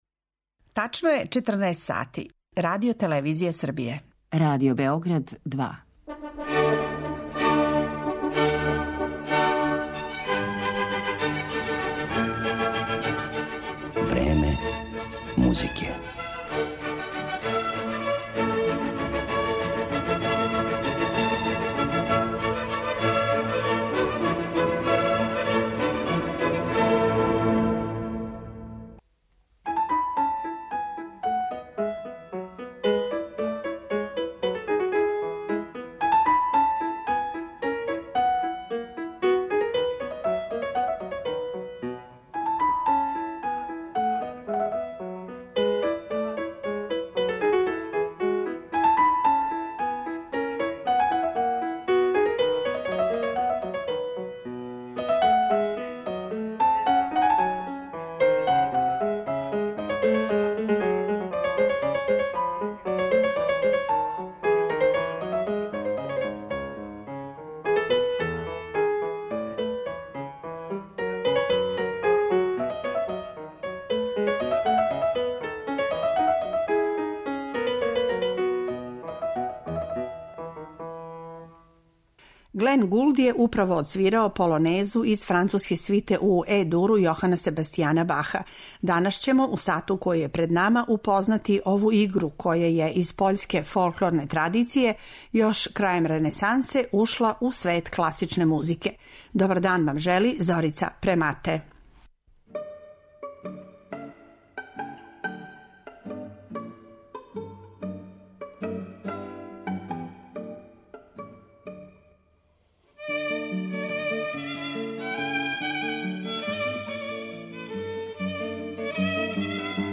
Између осталих, полонезе су компоновали и Бах, Бетовен, Моцарт, Хендл, Паганини, Вебер, Дворжак, Чајковски, Љадов, Вјењавски и Шопен, а неке од тих композиција имаћете прилике и да чујете у овој емисији.